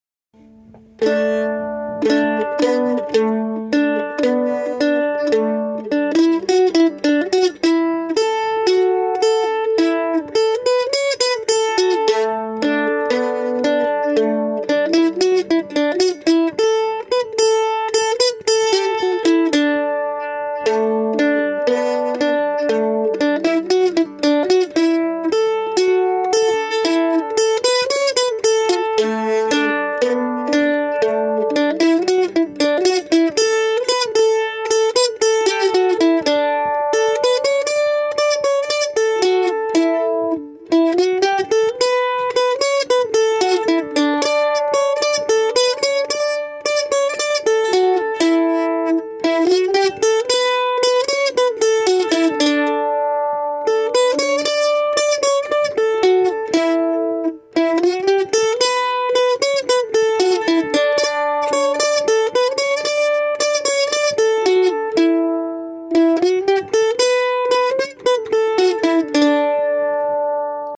Key: D
Form: Reel, Old-Time
Played slowly for learning